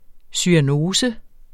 Udtale [ syaˈnoːsə ]